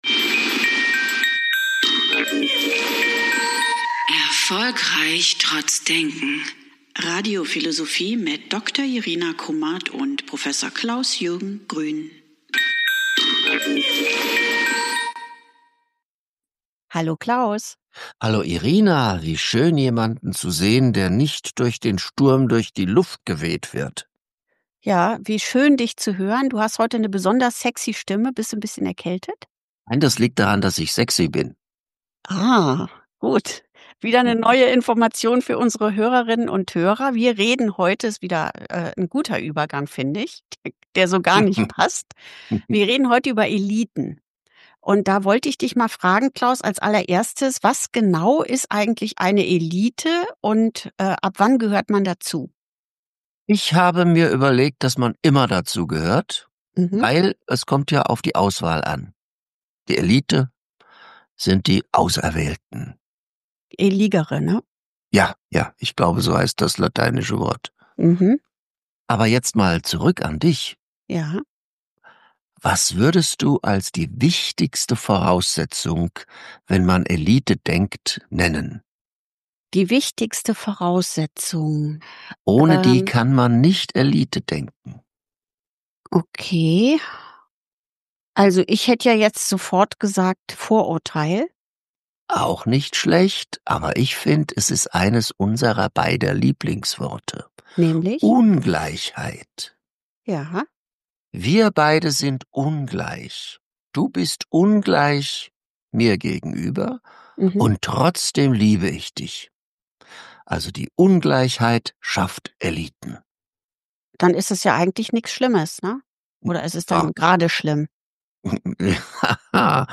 im Gespräch über eine Gruppe von Menschen, die etwas Besonderes sind oder sein wollen.